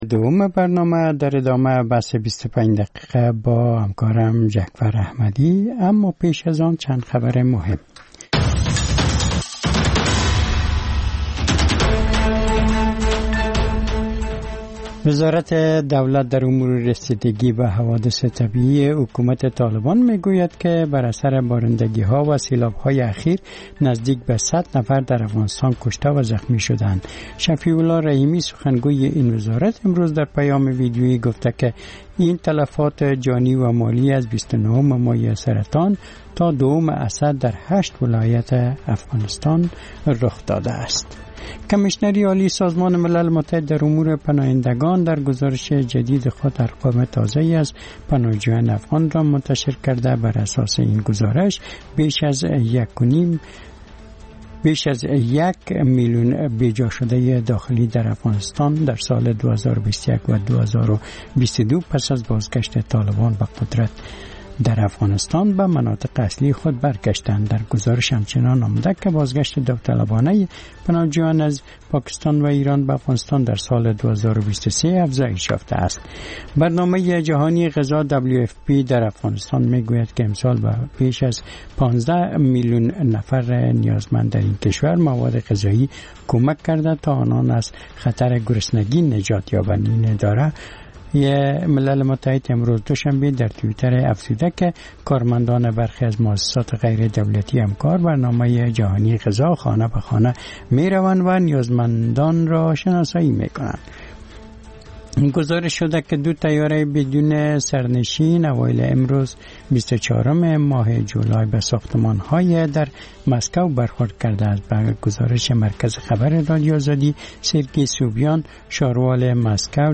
خبرهای کوتاه - میز گرد